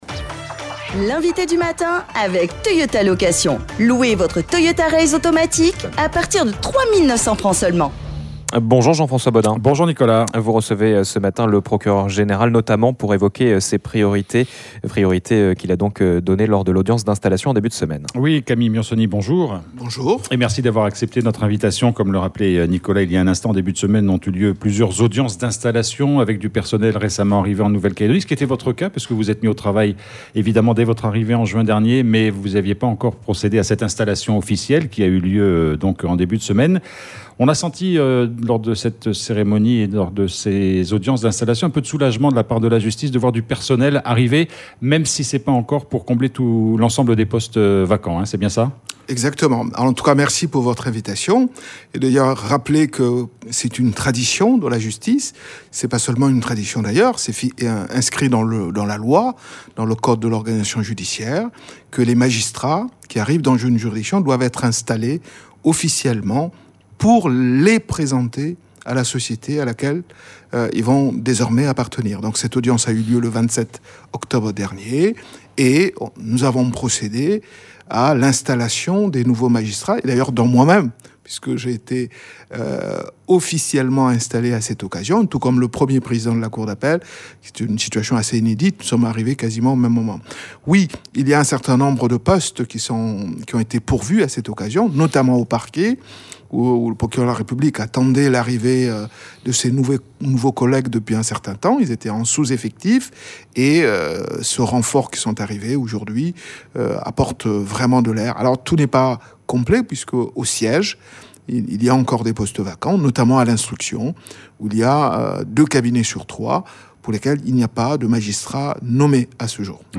Et d’une façon générale, la politique pénale en Calédonie a-t-elle évoluée ? Des questions que nous avons posé au procureur général Camille Miansoni.